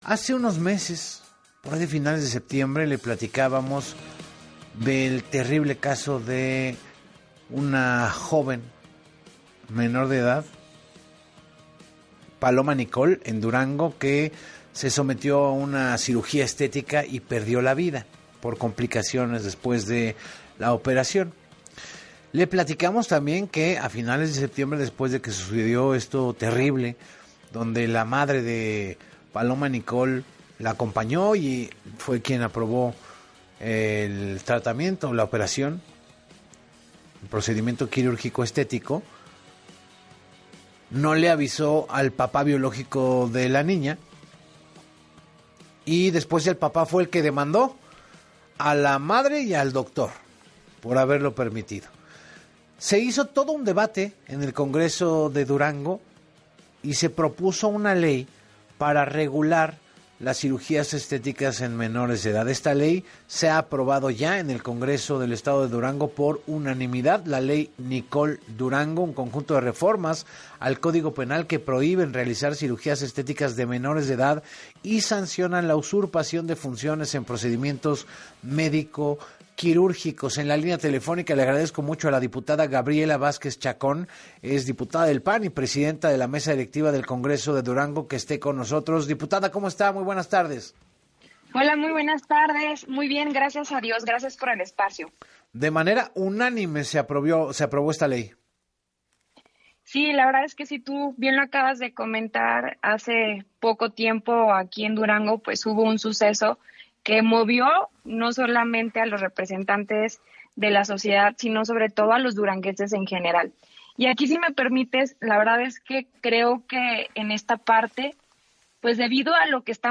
En entrevista para “Así Las Cosas con Enrique Hernández Alcázar”, la diputada y presidenta de la Mesa Directiva del Congreso de Durango, Gabriela Vázquez Chacón, confirmó la aprobación de la iniciativa, y enfatizó que se busca alinear la legislación local con los tratados internacionales que protegen los derechos de los menores.